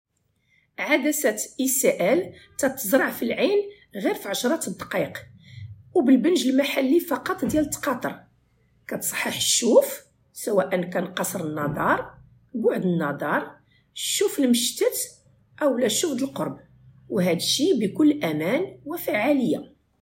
ICL_voice-over.ogg